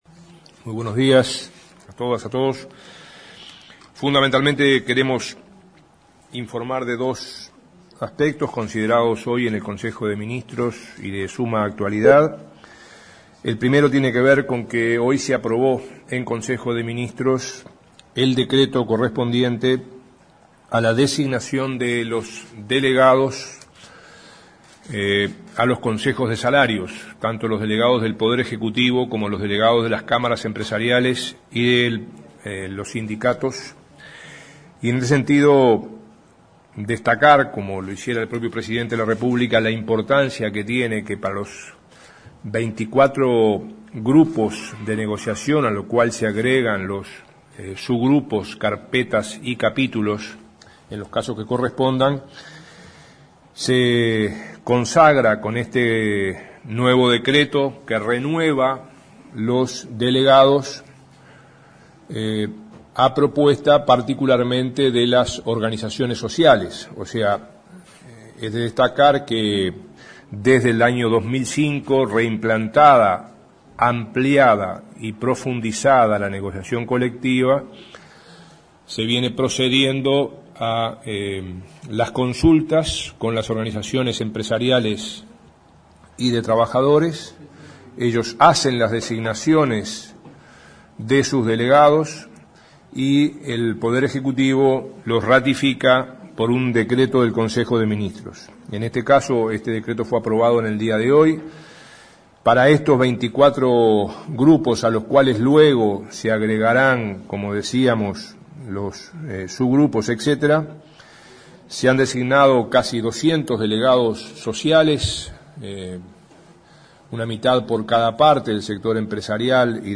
Murro afirmó en entrevista con Rompkbzas que "hay diálogo" con los sindicatos, aunque algunos digan lo contrario y explicó que en estos seis meses de gestión el equipo del MTSS "recorrió dos veces todo el país" en medio de reuniones "intensas y tensas".
Palabras de Ernesto Murro